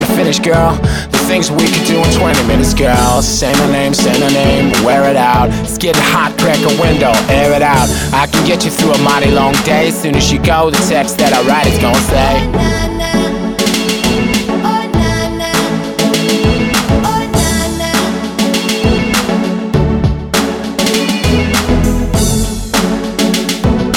Duet Version R'n'B / Hip Hop 4:26 Buy £1.50